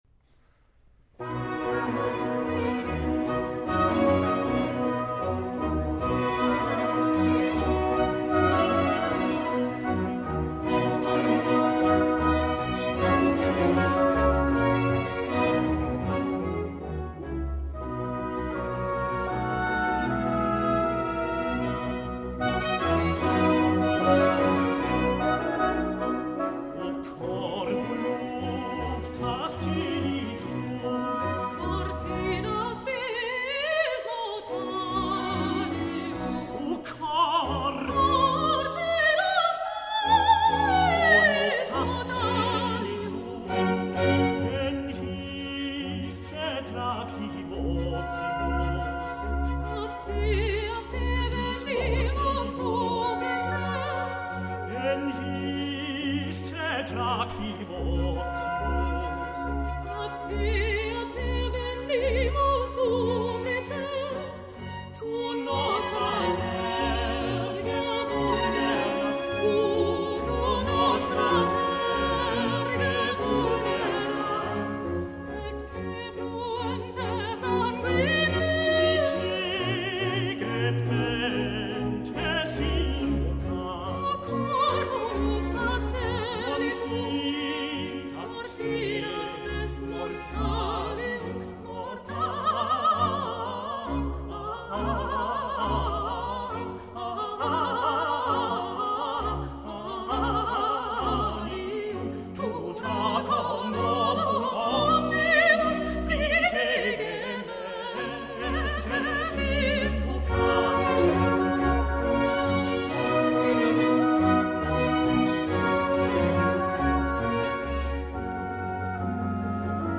• Geistliche Vokalmusik für Stift Stams
Tenor
Sopran